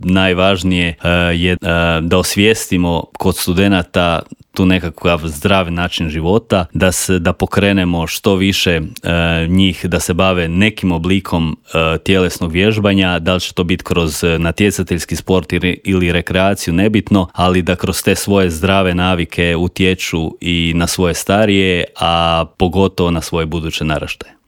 U intervjuu Media servisa